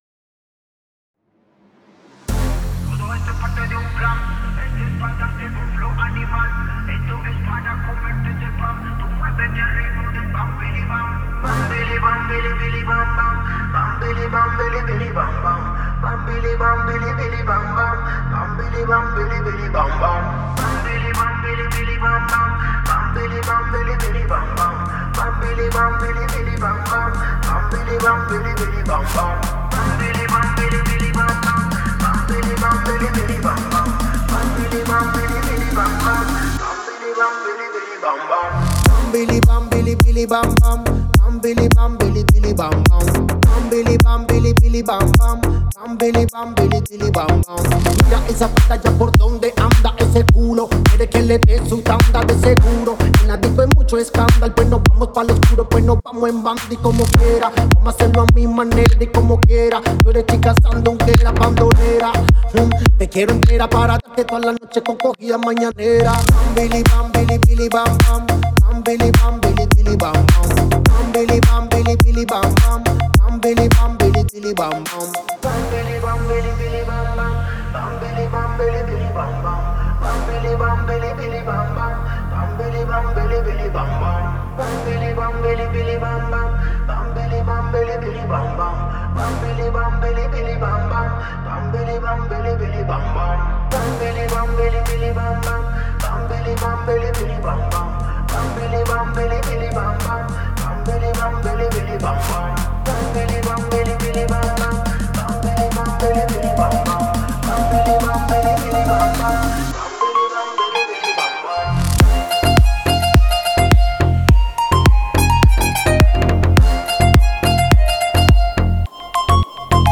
это энергичная и зажигательная песня в жанре поп
ритмичными битами и заразительным припевом